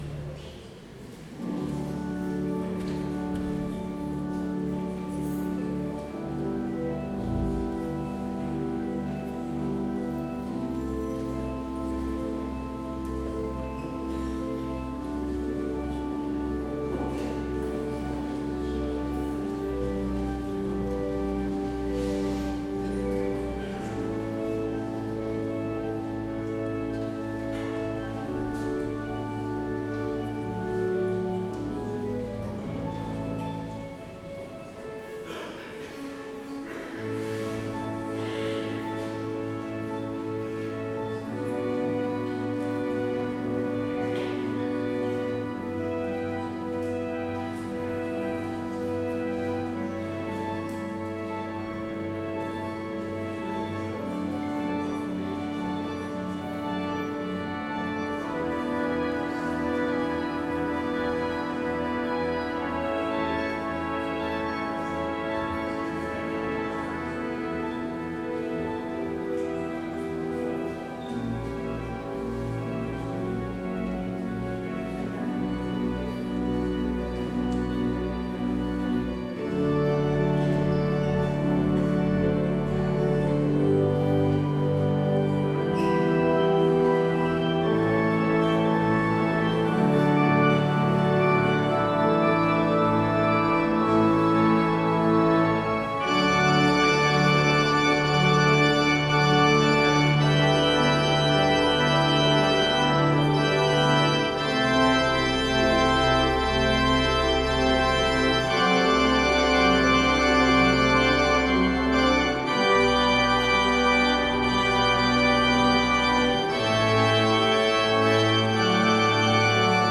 Heropening Sint-Pieterskerk Rotselaar
Zadok the Priest (enkel orgel)